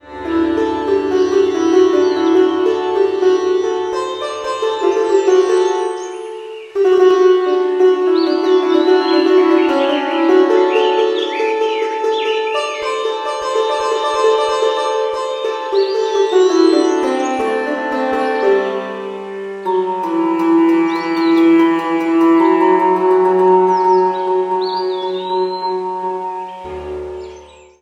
Мелодии на звонок
Нарезка на смс или будильник